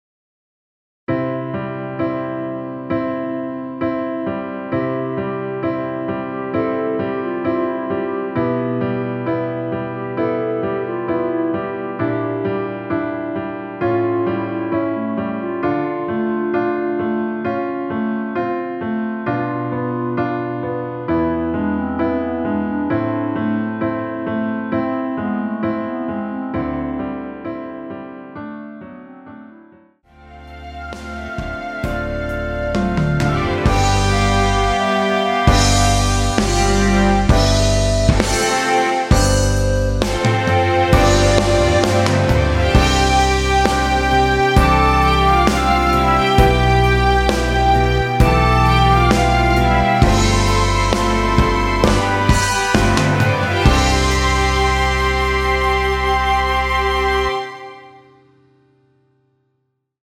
전주 없이 시작하는 곡이라 전주 1마디 만들어 놓았으며
원키 멜로디 포함된 MR입니다.(미리듣기 확인)
앞부분30초, 뒷부분30초씩 편집해서 올려 드리고 있습니다.
중간에 음이 끈어지고 다시 나오는 이유는